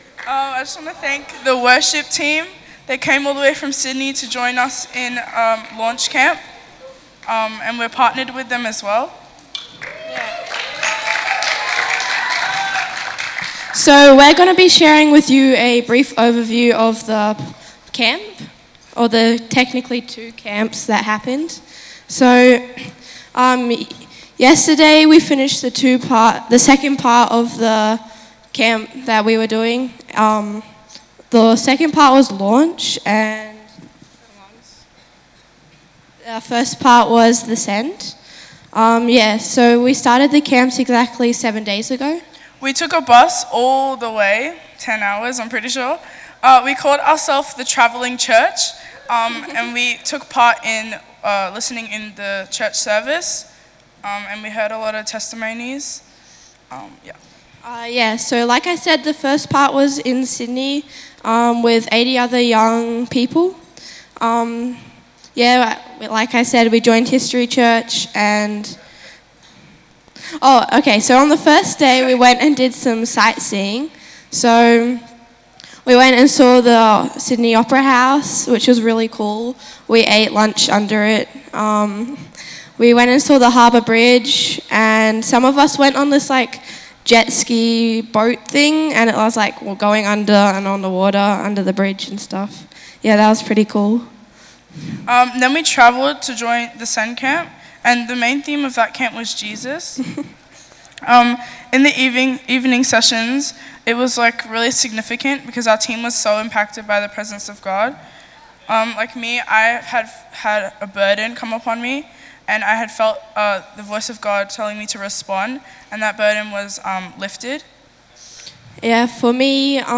The Discover and History youth share inspiring stories from the two youth camps this past week!